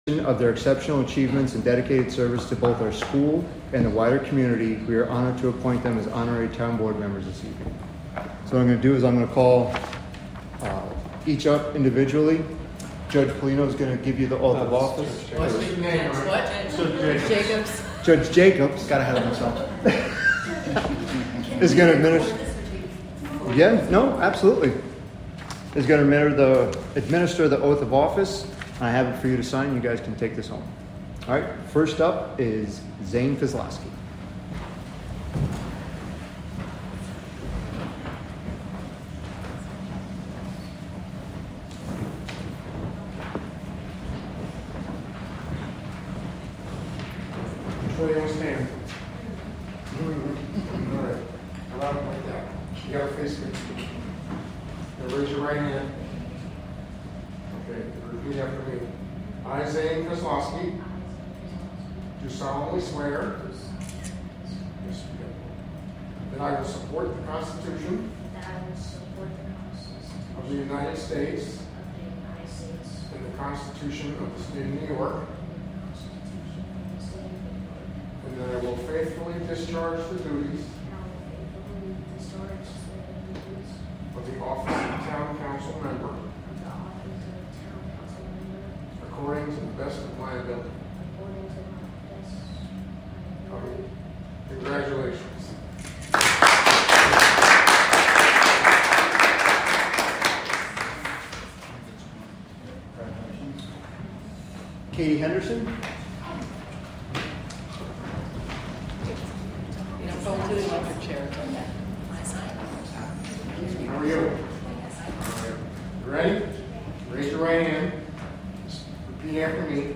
Live from the Town of Catskill: July 2, 2024 Catskill Town Board Meeting (Audio)